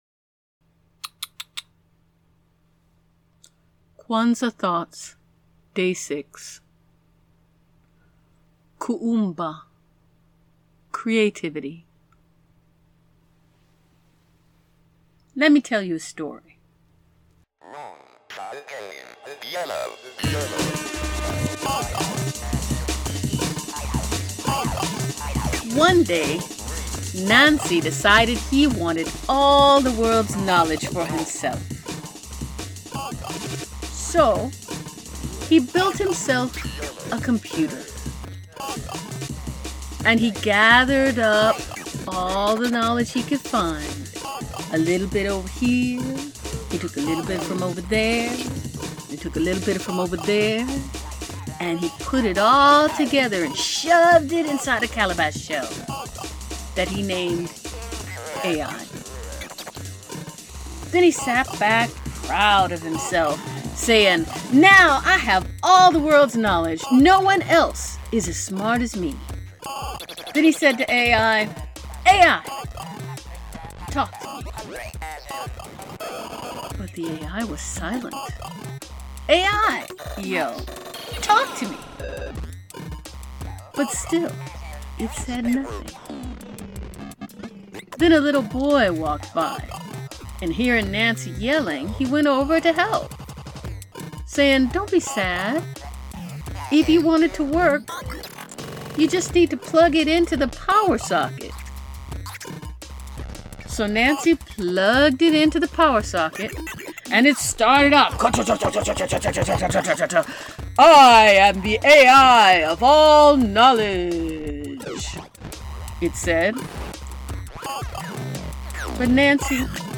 In the spirit of Kuumba, for Today's affirmation I will tell a Nancy story.